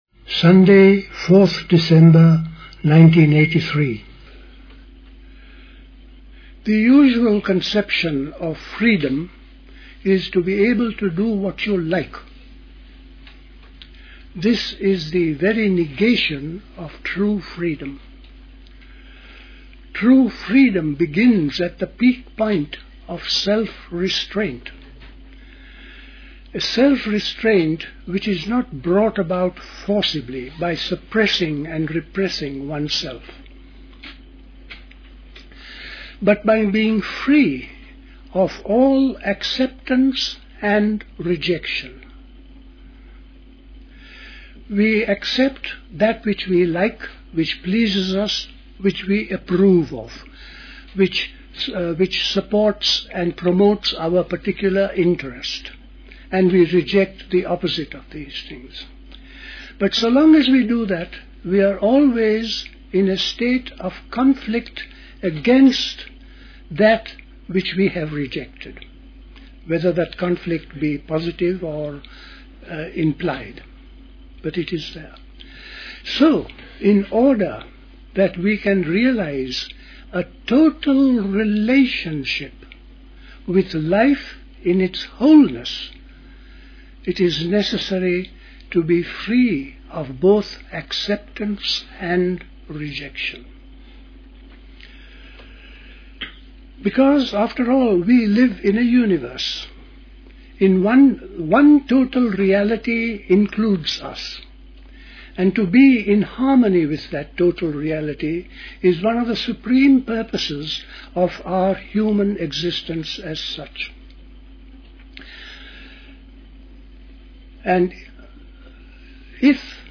A talk
at Dilkusha, Forest Hill, London on 4th December 1983